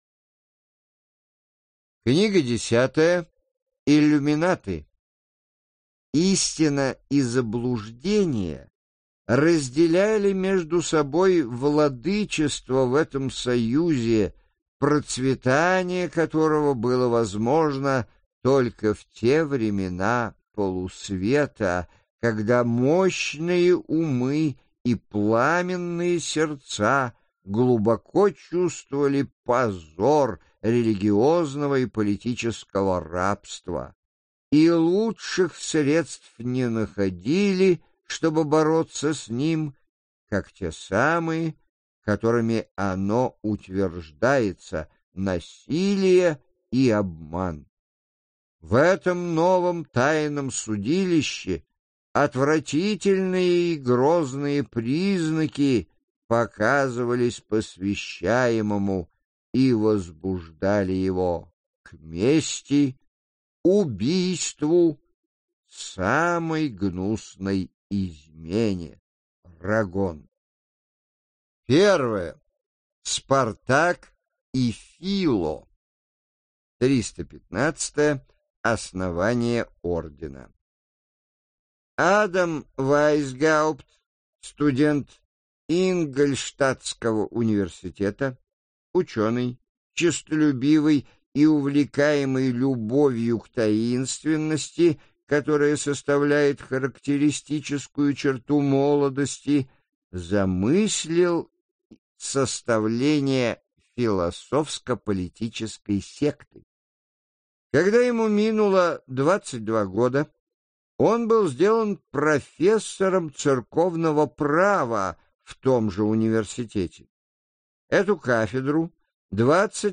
Аудиокнига Тайные общества всех веков и всех стран | Библиотека аудиокниг